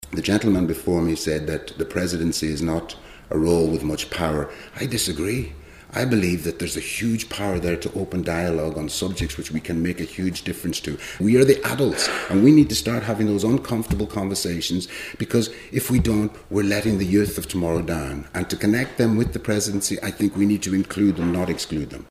But Donegal artist Kevin Sharkey doesn’t think that means the role is limited: